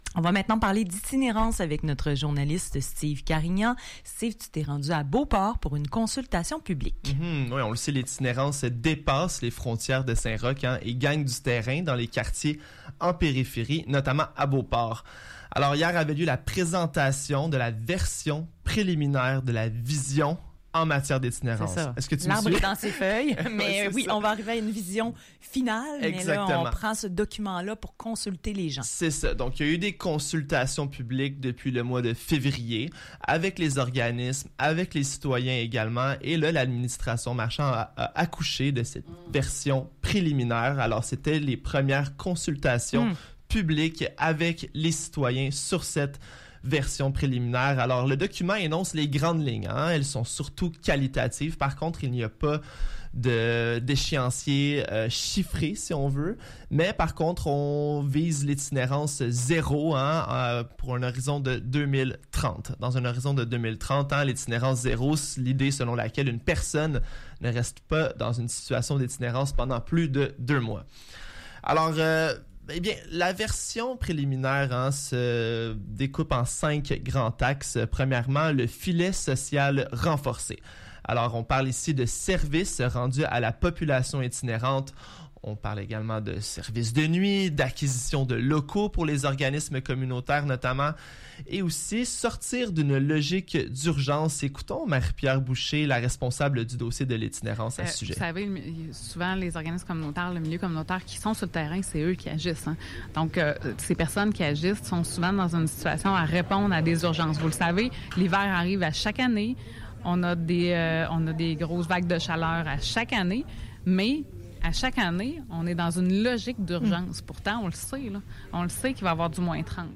reportage-itinerance-1.mp3